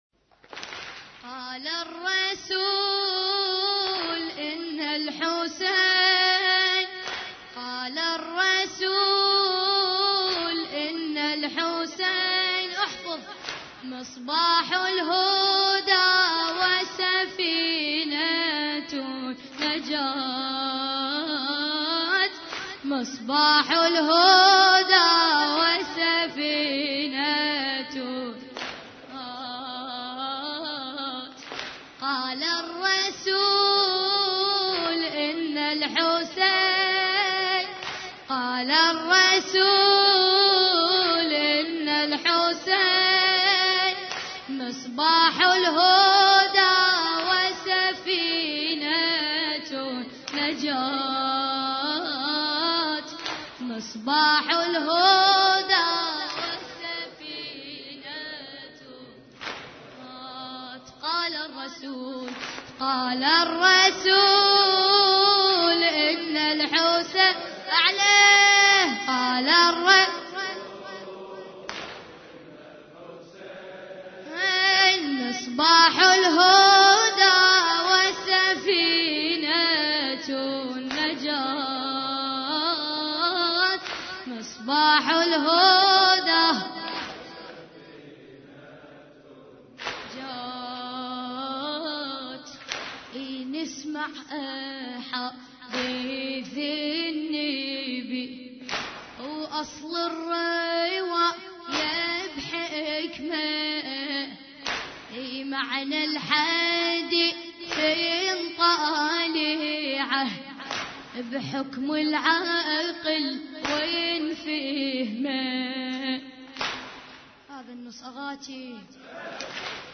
عزاء ليلة 2 محرم 1433 هجري